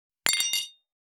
330食器,テーブル,チーン,カラン,キン,コーン,チリリン,カチン,チャリーン,クラン,カチャン,クリン,シャリン,チキン,
コップ